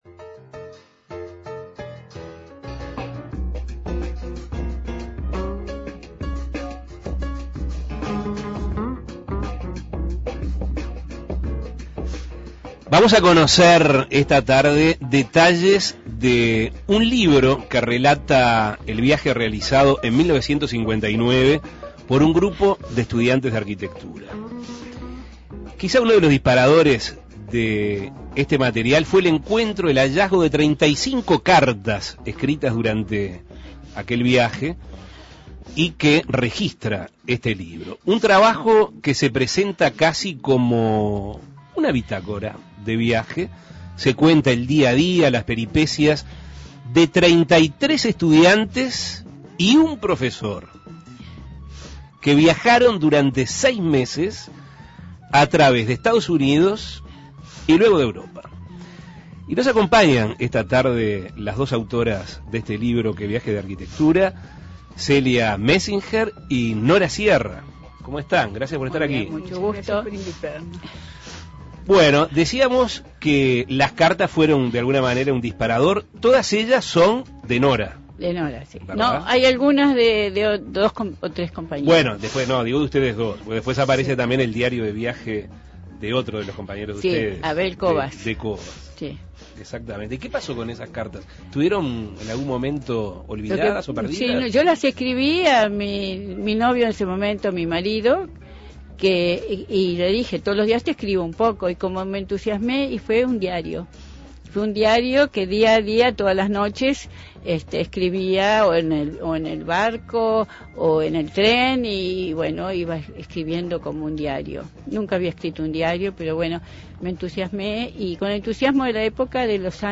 Este trabajo se presenta casi como una bitácora de viaje, en donde se cuenta día a día las peripecias de 33 estudiantes y su profesor, quienes viajaron durante seis meses a través de los Estados Unidos y Europa. Escuche la entrevista.